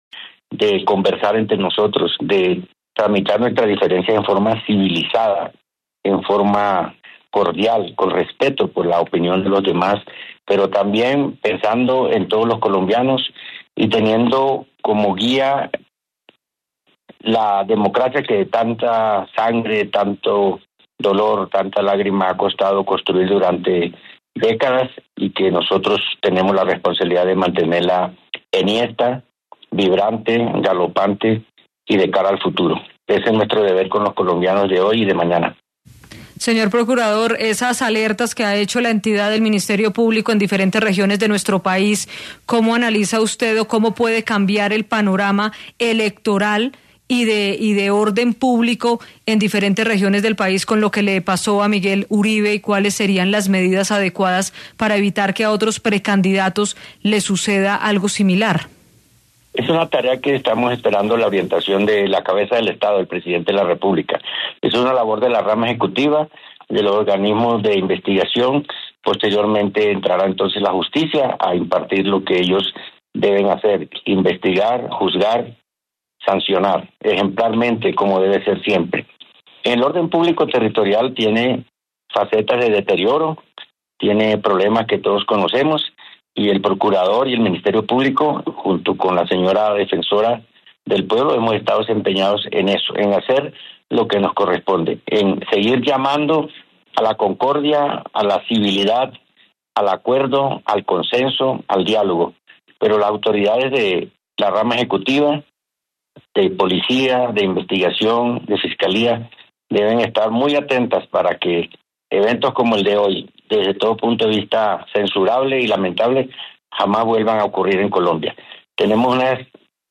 En diálogo con Caracol Radio el jefe del Ministerio Público hizo un llamado a la creación de consensos.